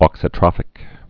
(ôksə-trŏfĭk, -trōfĭk)